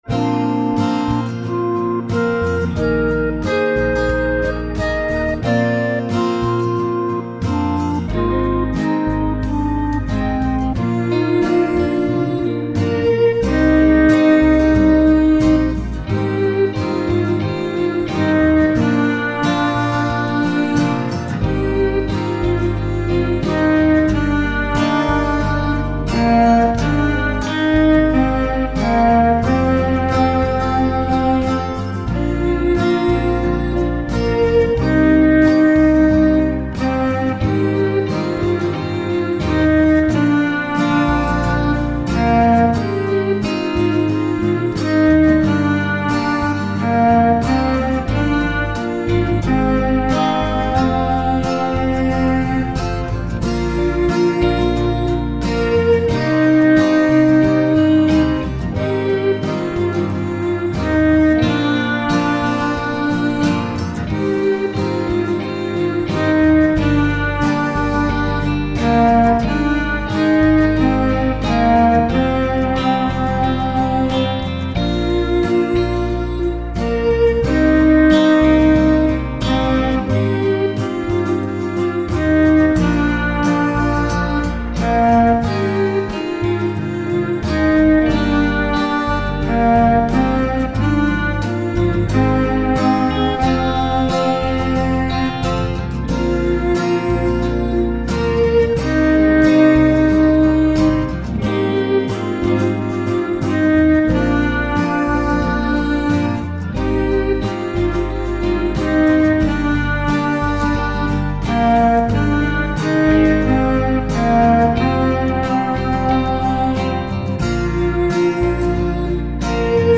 This is a very nice relaxing setting to pray on your own or with others.